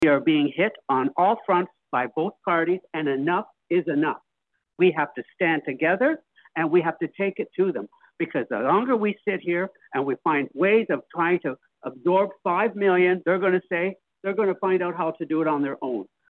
Meanwhile there was some anger at a meeting of the Hastings County Community and Human Services Committee meeting Wednesday.